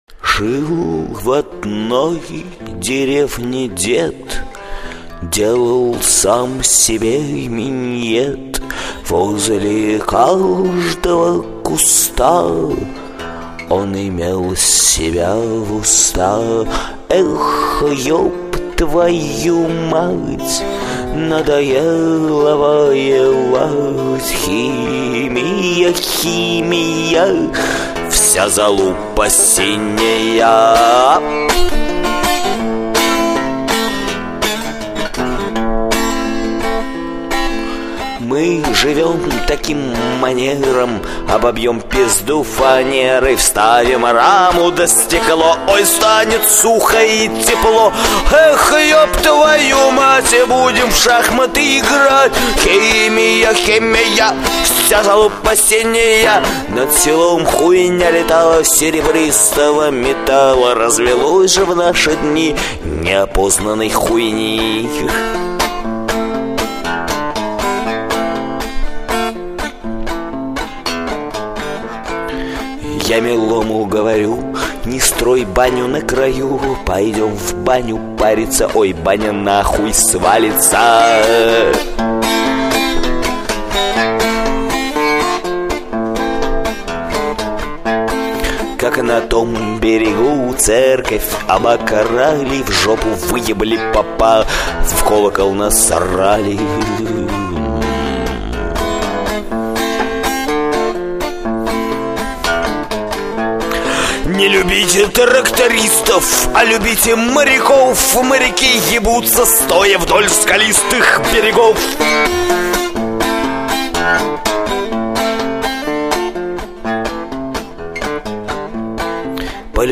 chastuwka____quot.mp3